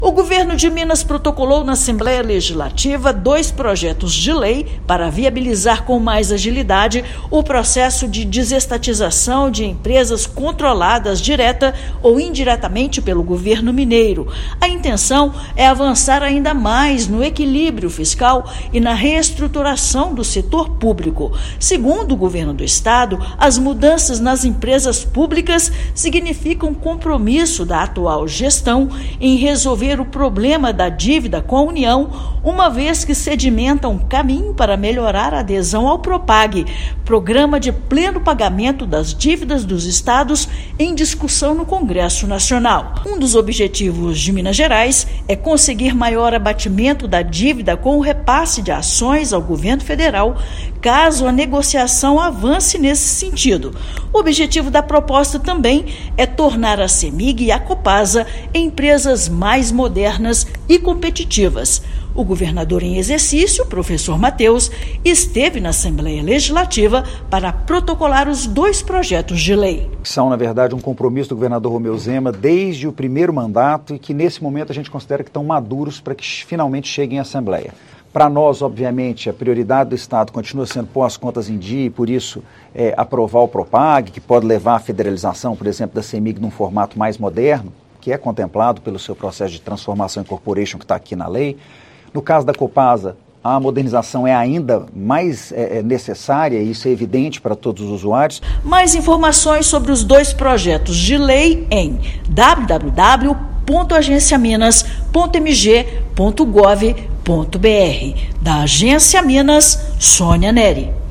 [RÁDIO] Governo de Minas envia à ALMG proposta para modernizar Cemig e Copasa
Objetivo é uma administração mais eficiente em companhias que representam melhorias na prestação de serviços aos mineiros. Ouça matéria de rádio.